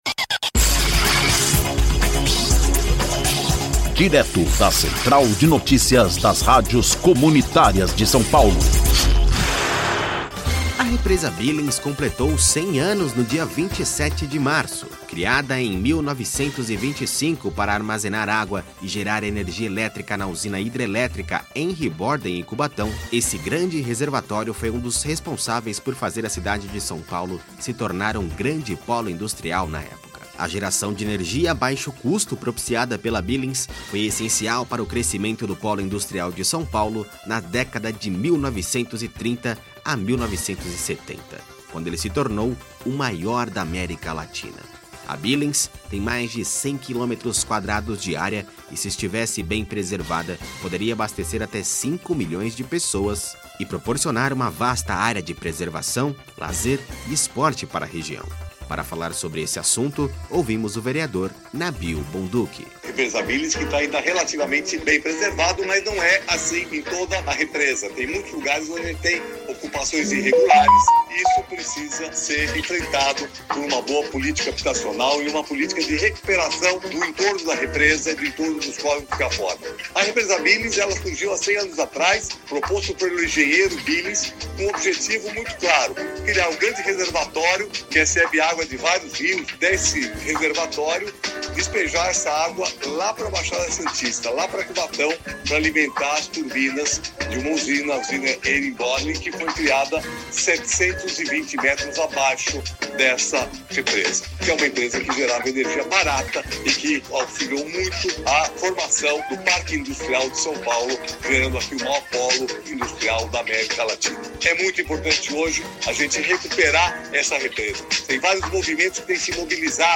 Para falar sobre este assunto ouvimos o vereador Nabil Bonduki